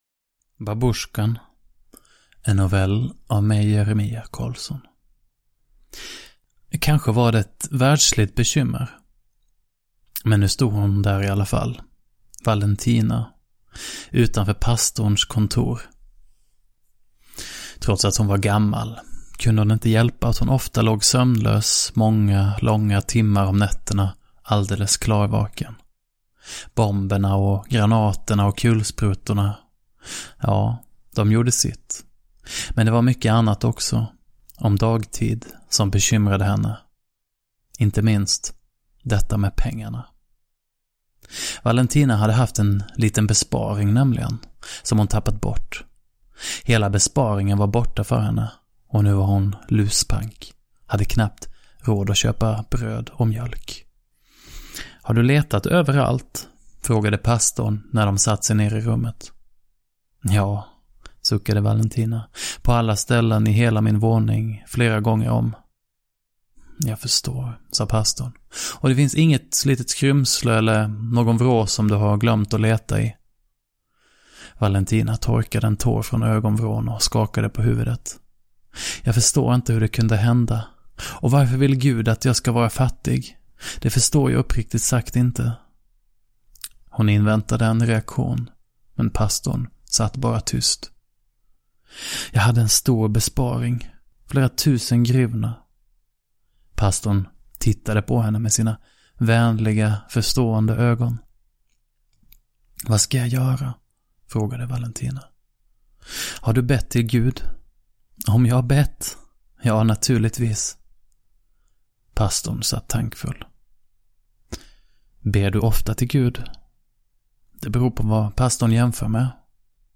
Ukrainska noveller – Ljudbok